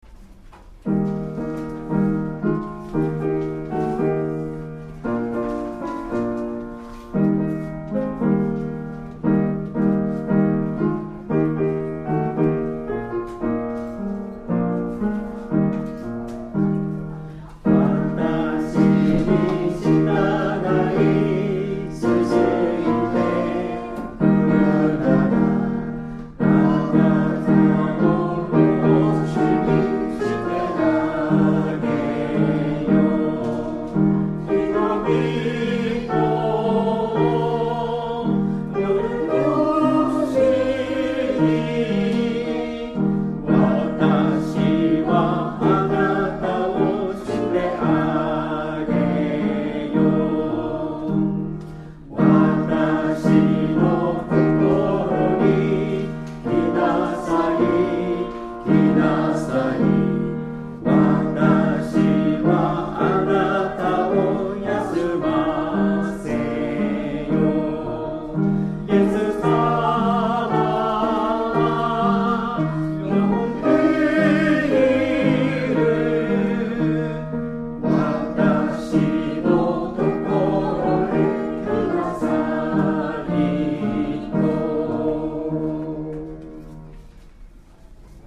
Tonality =Es Pitch = 440 Temperament = Equal